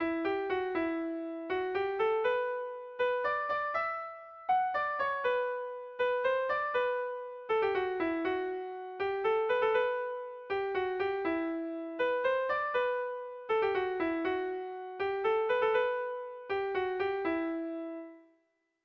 Erromantzea
ABDE